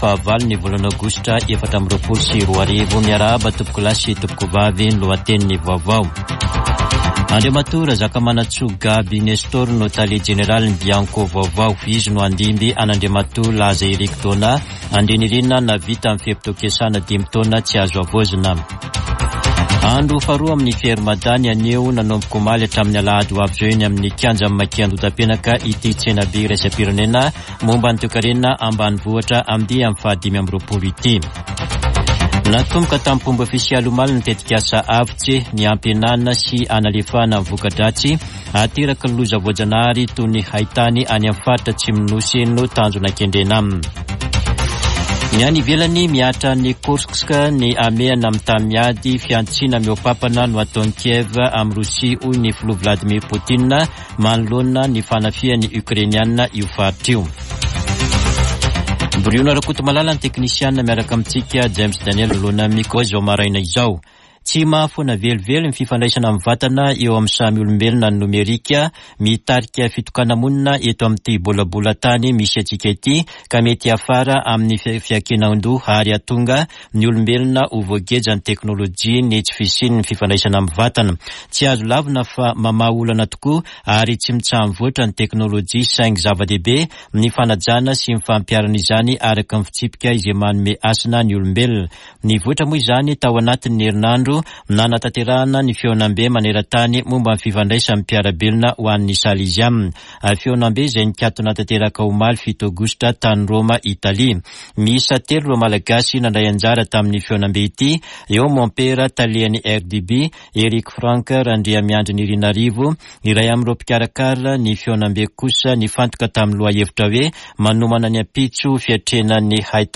[Vaovao maraina] Alakamisy 8 aogositra 2024